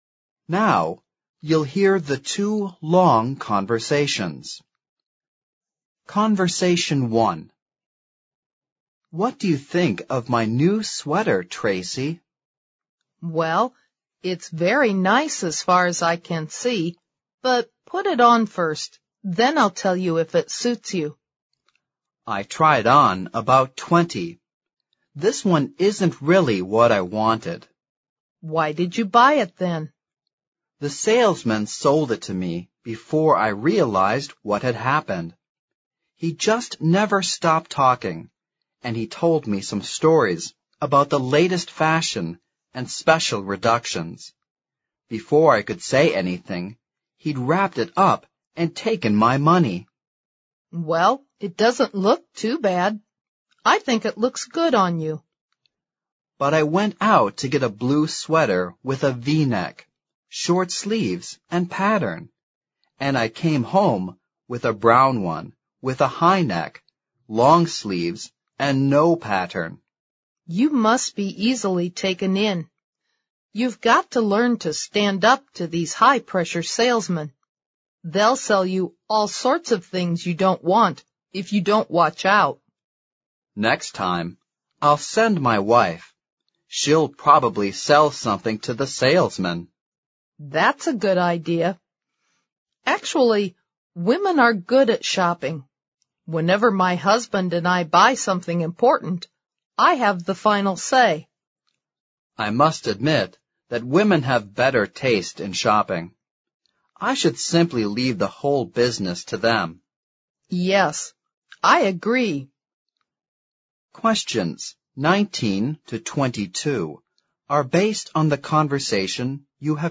Now you'll hear the two long conversations.